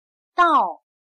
/dào/A, hasta